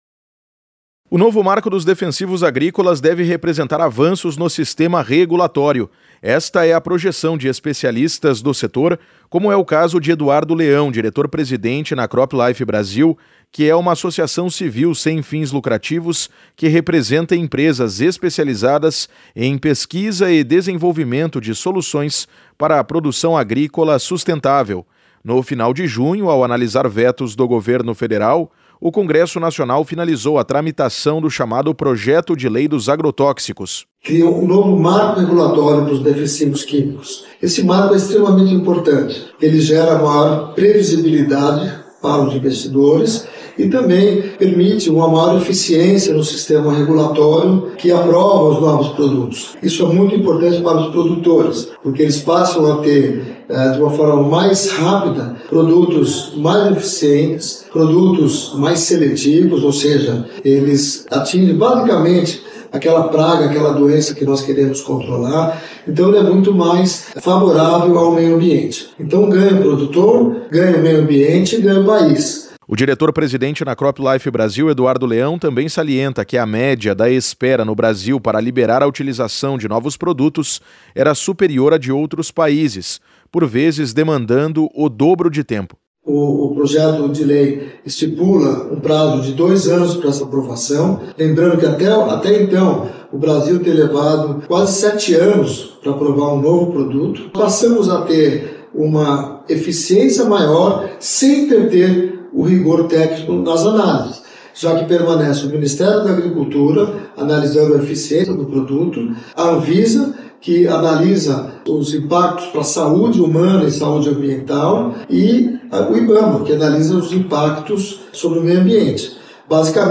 [Rádio] Defensivos agrícolas: nova lei pode gerar mais eficiência - CropLife